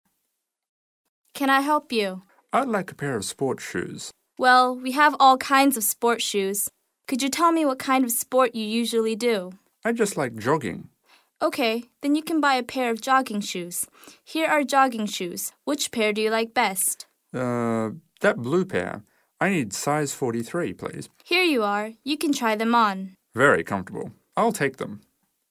英语口语900句 01.02.对话.2.买运动鞋 听力文件下载—在线英语听力室